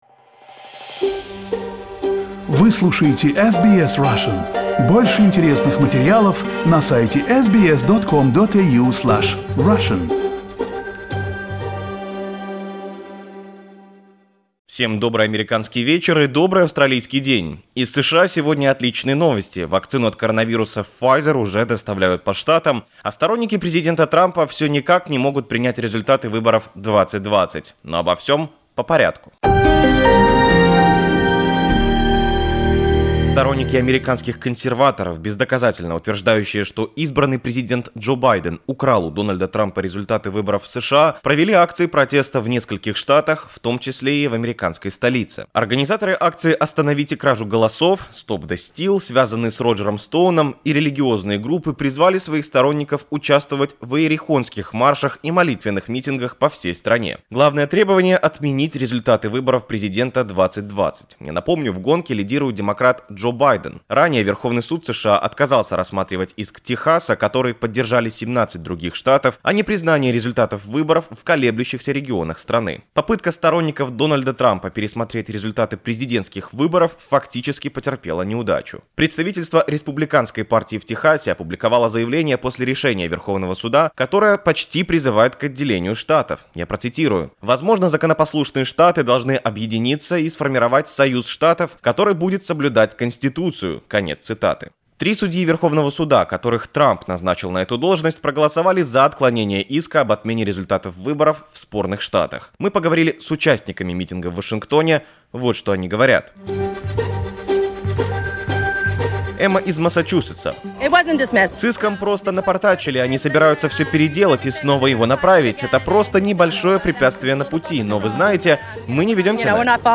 Report from Washington: Trucks with Vaccine and Trump Supporters Continue to Protest